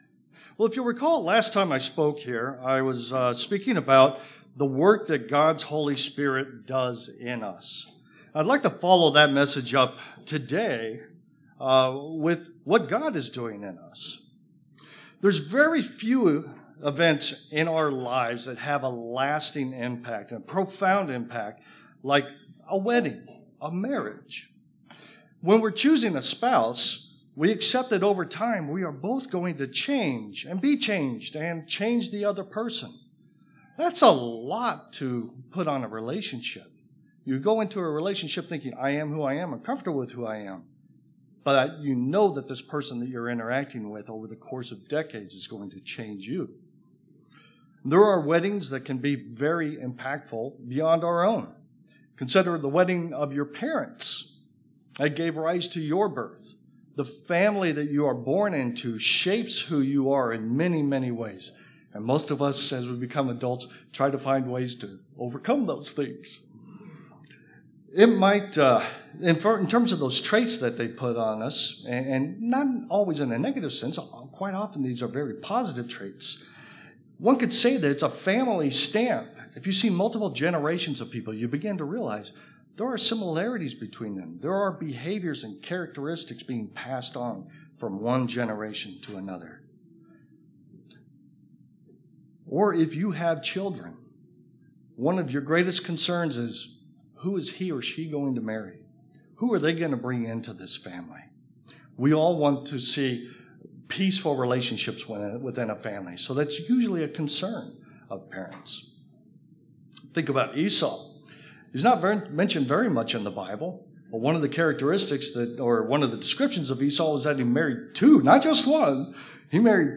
Split-sermon
2022 in Munster, Indiana congregation.